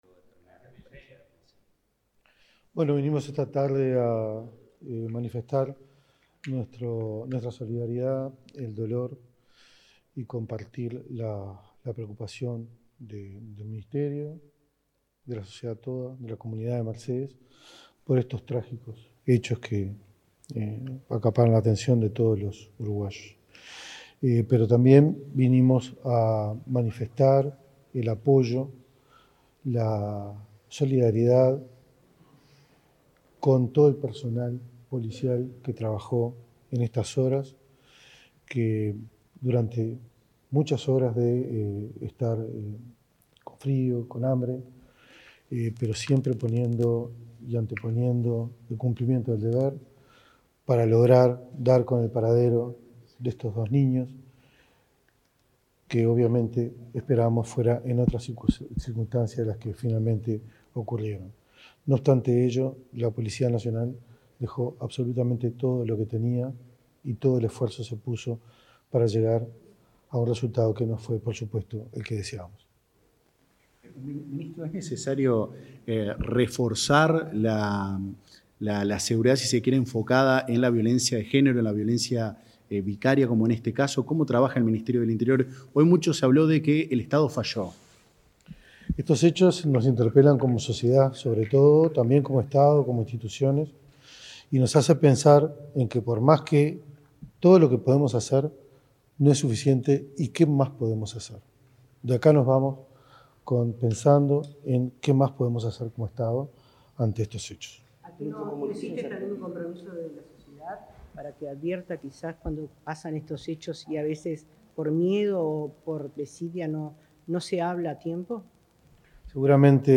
El ministro del Interior, Carlos Negro, brindó declaraciones a la prensa en la ciudad de Mercedes, departamento de Soriano, tras la tragedia ocurrida